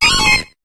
Cri de Farfuret dans Pokémon HOME.